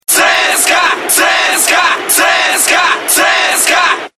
Футбольные кричалки